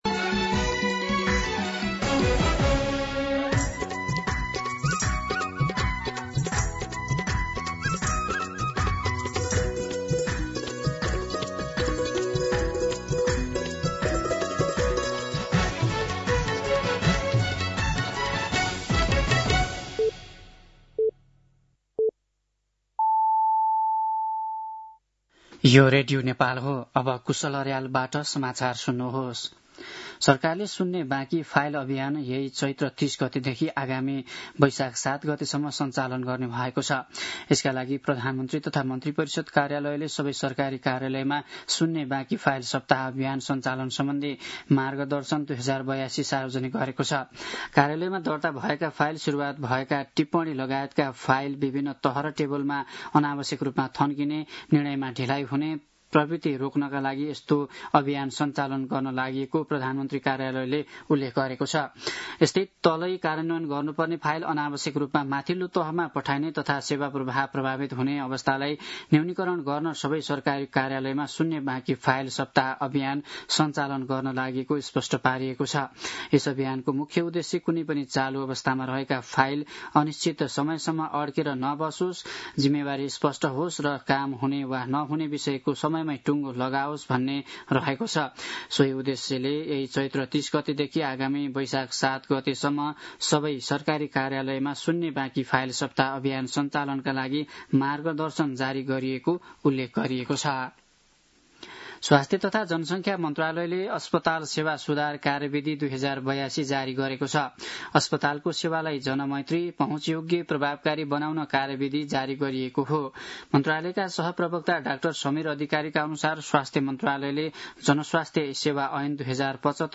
दिउँसो १ बजेको नेपाली समाचार : २८ चैत , २०८२